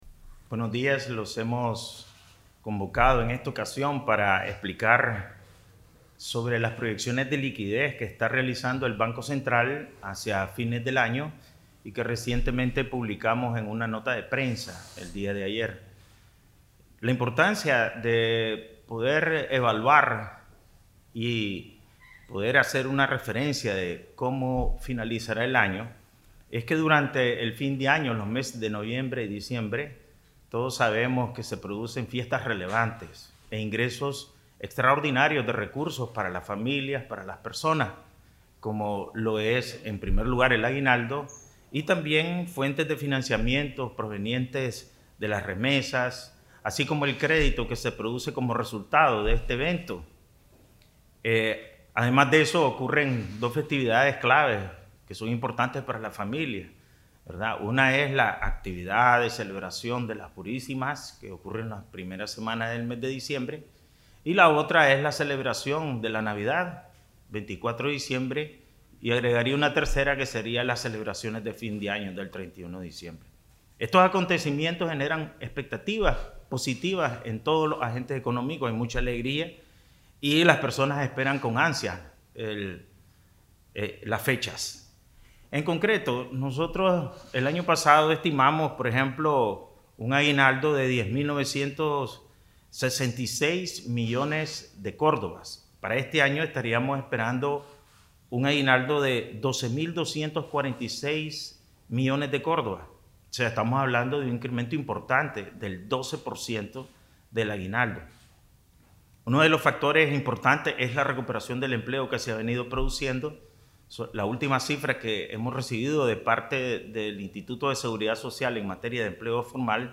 Transcripción de Conferencia de Prensa, Presidente del BCN, Ovidio Reyes R.
Palabras_AS_en_conferencia_de_prensa.mp3